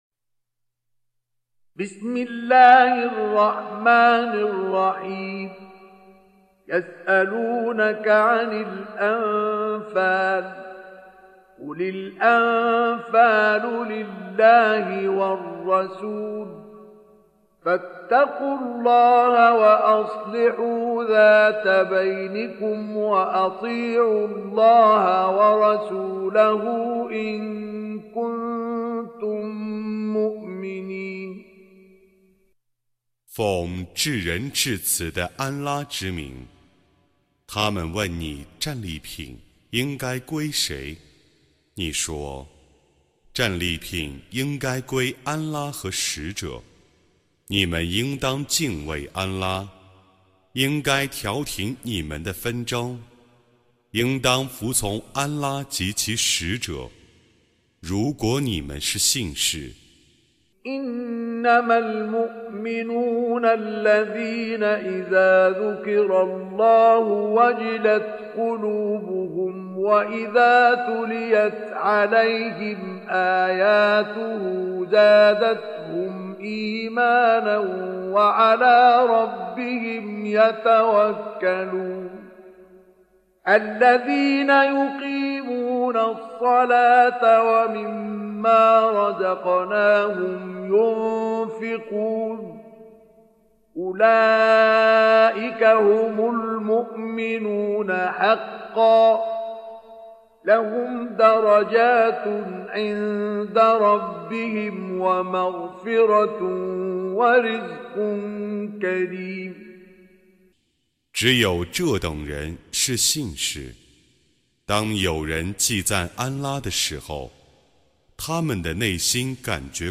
Surah Repeating تكرار السورة Download Surah حمّل السورة Reciting Mutarjamah Translation Audio for 8. Surah Al-Anf�l سورة الأنفال N.B *Surah Includes Al-Basmalah Reciters Sequents تتابع التلاوات Reciters Repeats تكرار التلاوات